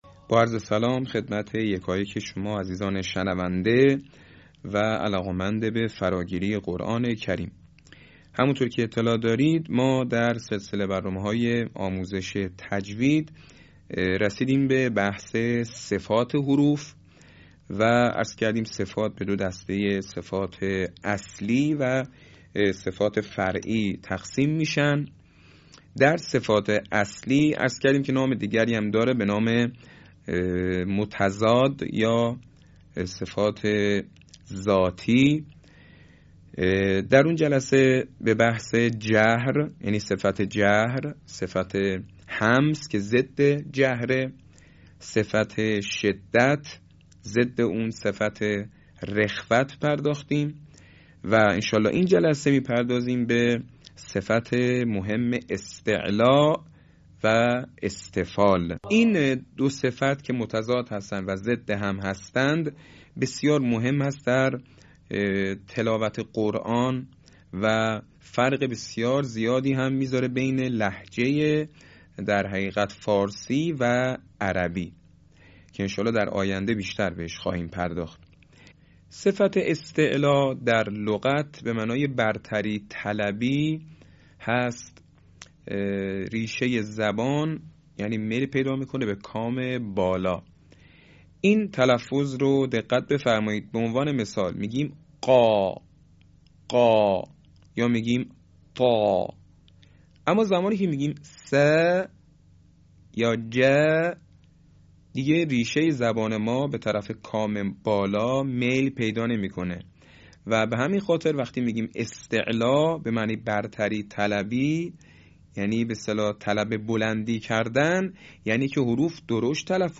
صوت | آموزش تجوید صفت استعلاء و استفال